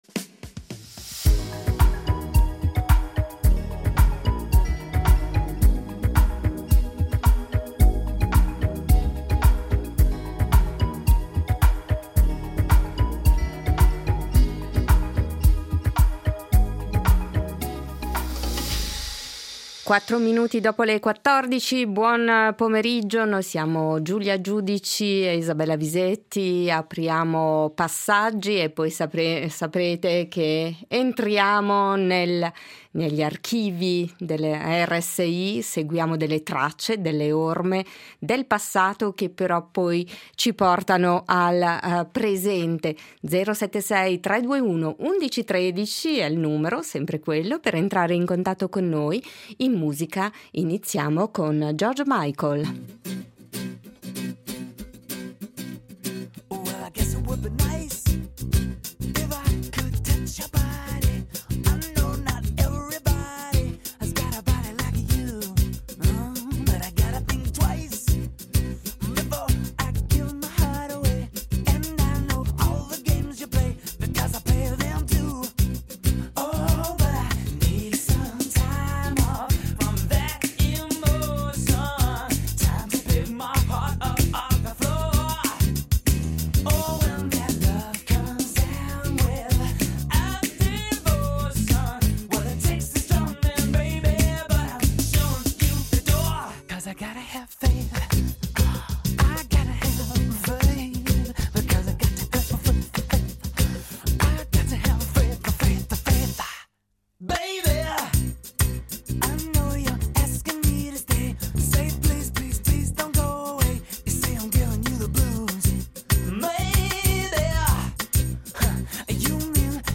Amsterdam Contenuto audio Disponibile su Scarica In Tracce, estratti d’archivio su Van Gogh, pittore olandese autore di quasi novecento dipinti e di più di mille disegni. Ci sarà anche un frammento d’archivio sulla nascita della nuova associazione “Scoutismo Ticino” e sulla soubrette Wanda Osiris.